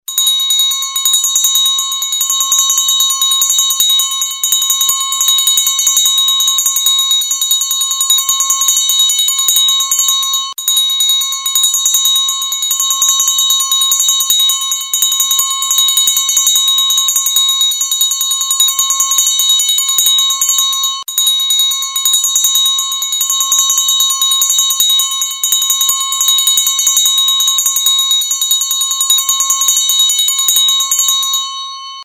Звуки школьной перемены
Звон колокольчика в момент последнего звонка